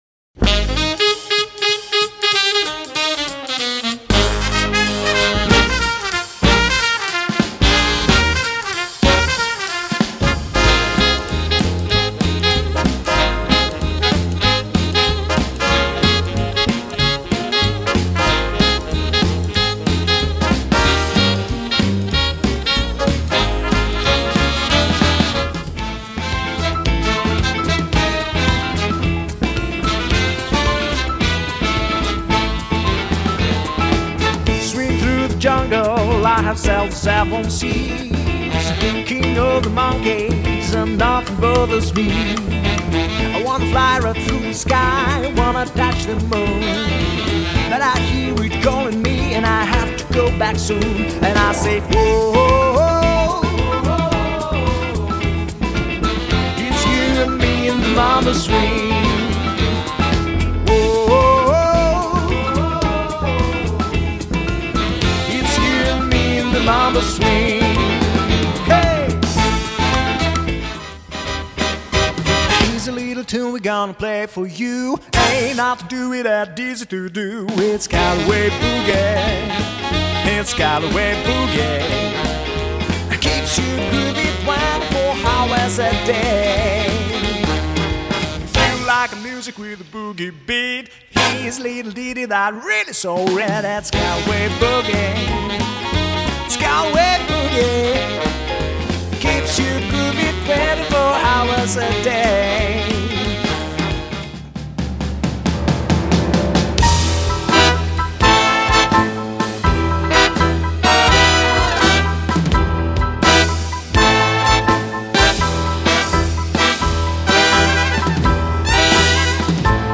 • Традиционный джаз;